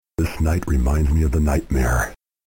"This night reminds me of the nightmare" in a voice so gruff, Solid Snake would be jealous, but even better...